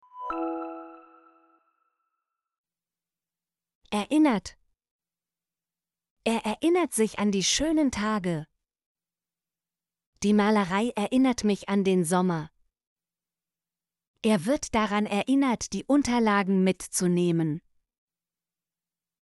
erinnert - Example Sentences & Pronunciation, German Frequency List